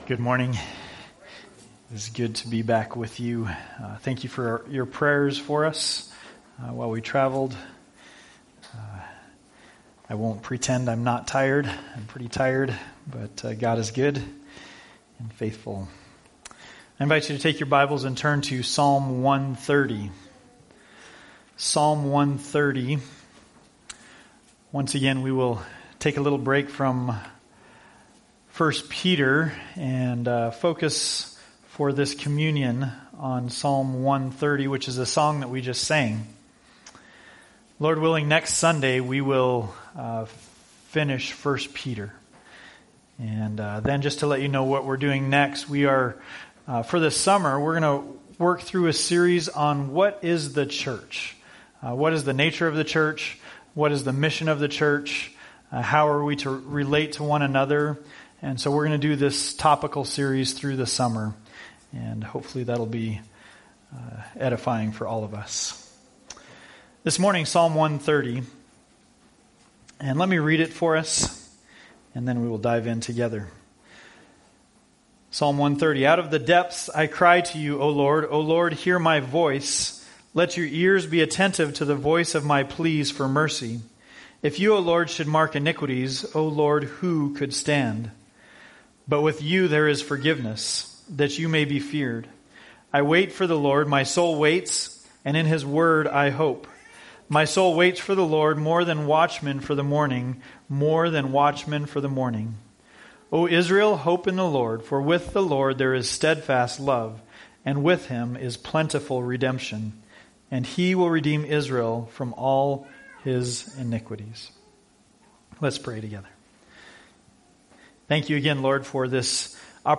A Communion Meditation: Forgiveness